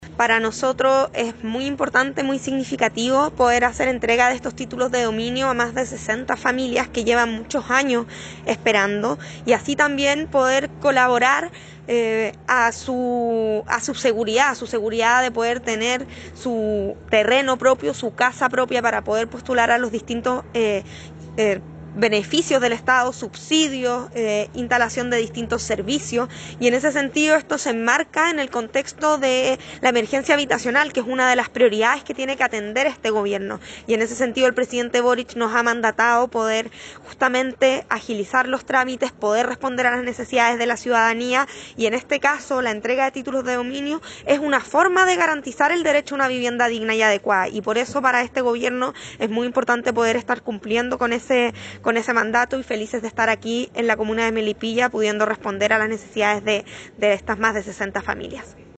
Tras una significativa ceremonia, más de 60 familias de la comuna de Melipilla recibieron sus ansiados Títulos de Dominio, en una actividad presidida por la Ministra de Bienes Nacionales, Javiera Toro; la Delegada Presidencial Provincial, Sandra Saavedra; el seremi de Bienes Nacionales, Germán Pino; y la alcaldesa Lorena Olavarría.